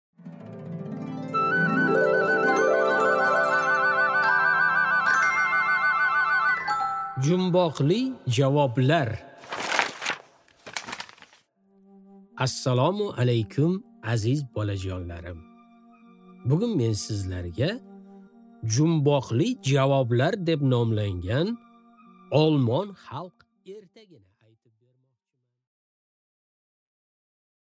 Аудиокнига Jumboqli javoblar